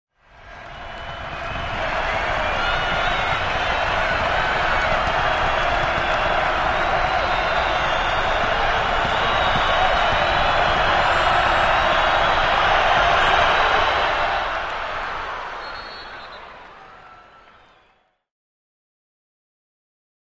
Large Basketball Crowd Steady Cheering And Wash